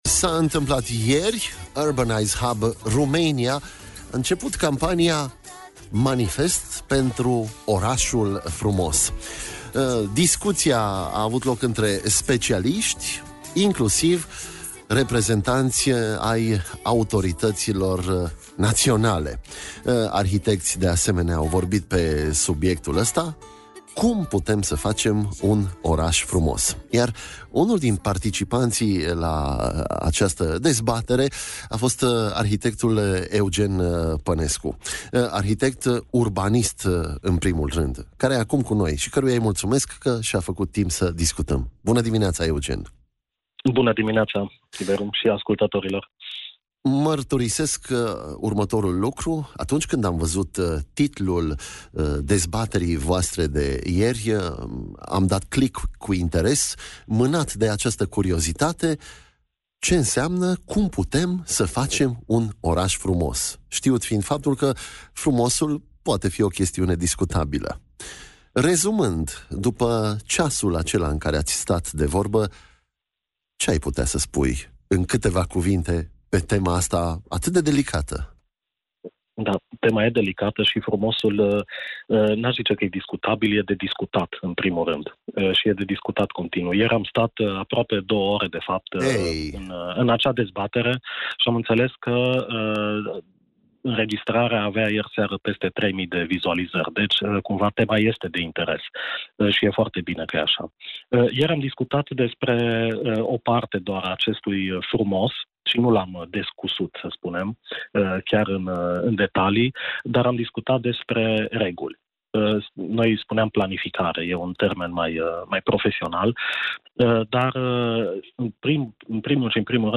discuția